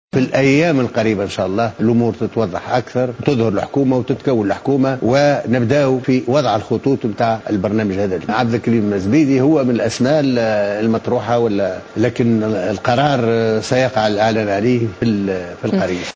قال رئيس مجلس نواب الشعب، محمد الناصر في تصريح لقناة نسمة اليوم الثلاثاء إن مسألة تعيين رئيس الحكومة القادمة سيقع الاتفاق عليها في الايام القليلة القادمة مشيرا إلى أن عبد الكريم الزبيدي مرشح لتولي هذا المنصب.